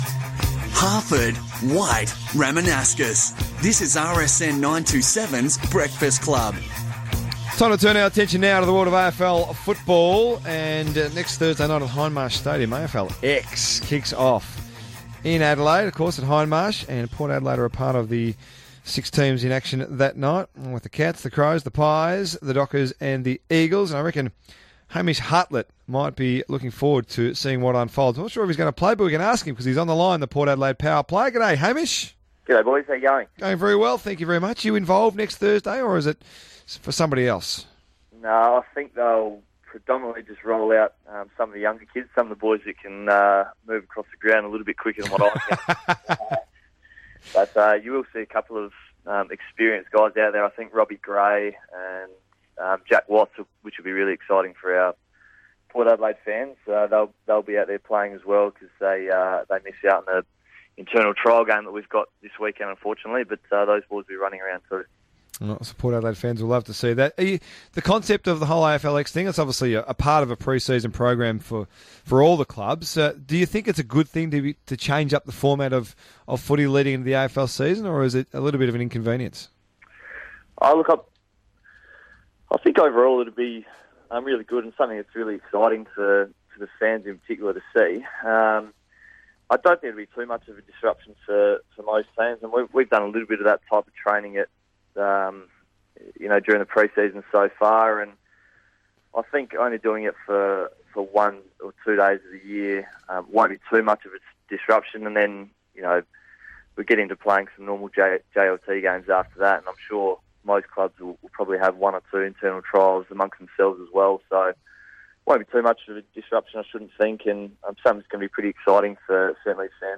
on Melbourne radio station RSN